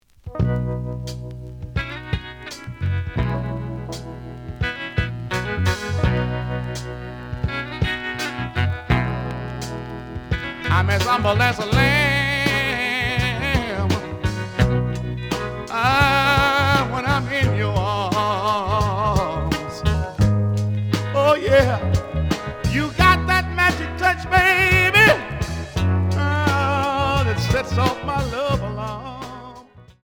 試聴は実際のレコードから録音しています。
●Genre: Soul, 70's Soul
●Record Grading: VG+ (盤に若干の歪み。多少の傷はあるが、おおむね良好。プロモ盤。)